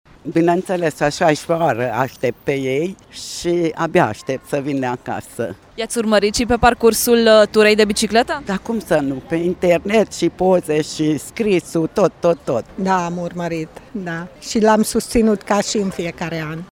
După nouă zile de pedalat și 550 de kilometri parcurși, echipa Caravana Bicicleta Radio Tîrgu-Mureș a încheiat ediția cu numărul 16 în fața magazinului Kaufland. Aici au avut parte de o întâmpinare cu aplauze din partea ascultătorilor care au ținut să le spună „Bun venit!”: